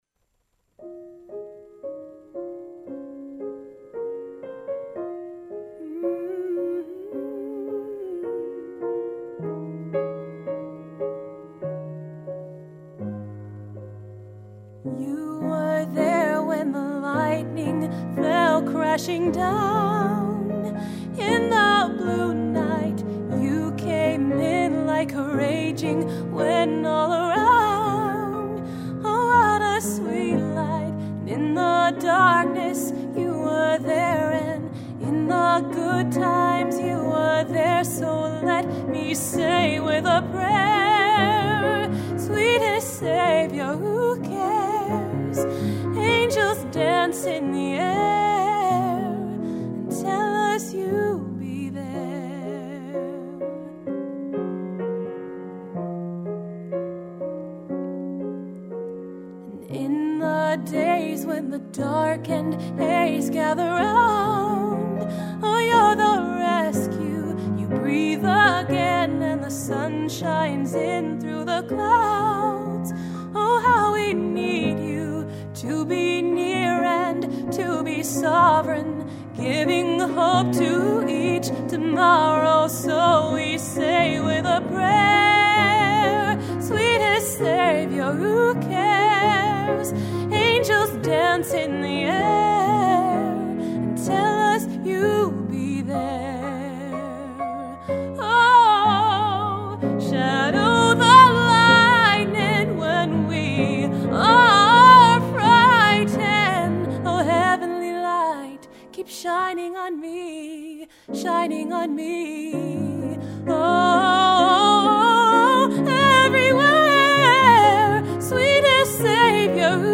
1) These are all first takes.